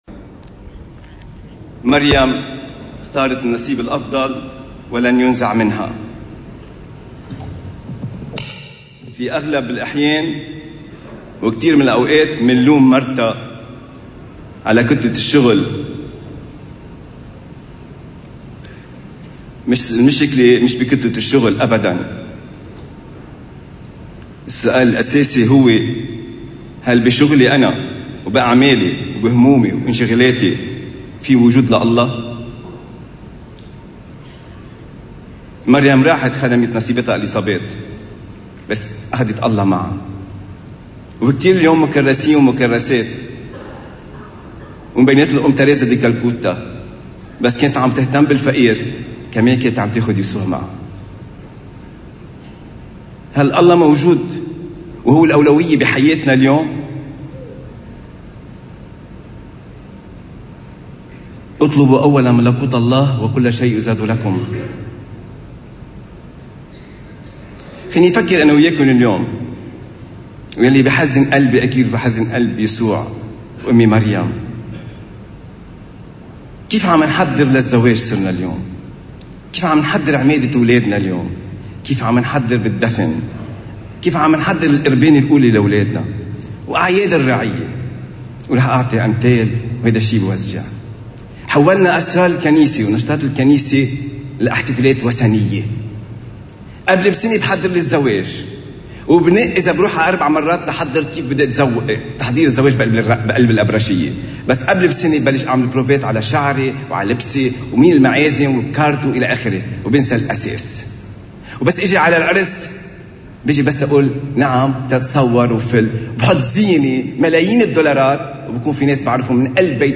عظة
قداس السلام في دير القديسة تريزيا الطفل يسوع – سهيلة 20 آب 2018